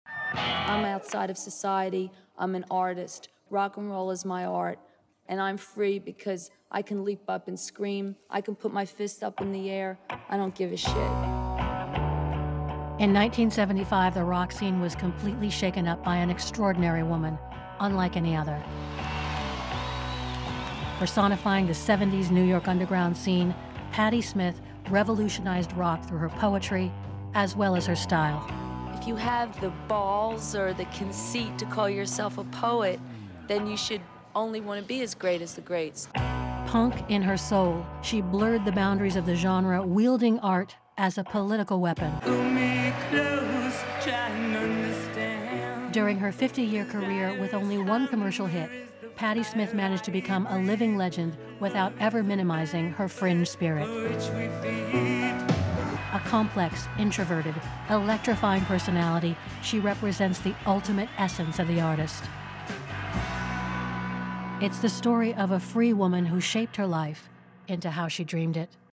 DOWNLOAD MP3 Patti Smith: Electric Poet An unvarnished narrative interpretation for an uncompromising artist.